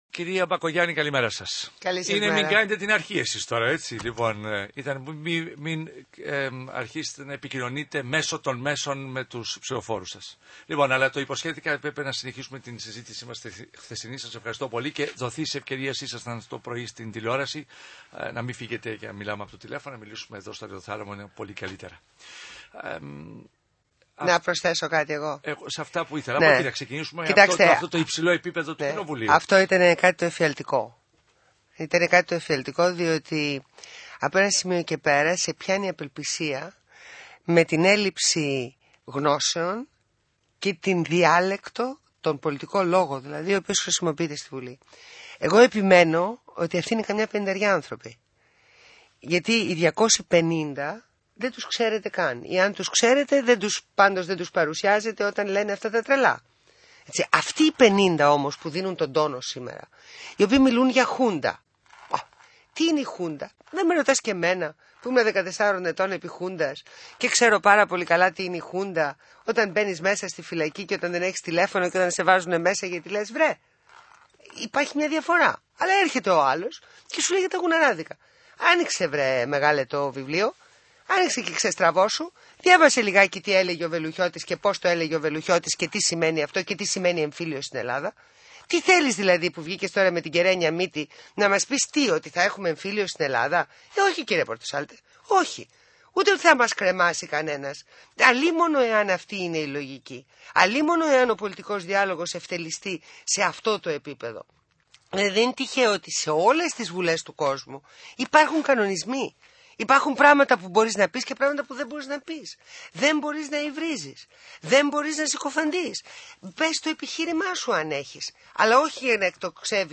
Συνέντευξη στο ραδιόφωνο του ΣΚΑΙ
Συνέντευξη στο δημοσιογράφο Αρη Πορτοσάλτε με αναφορές στο νέο πολυνομοσχέδιο, τη φορολογική πολιτική της κυβέρνησης, τη Χρυσή Αυγή κ.ά.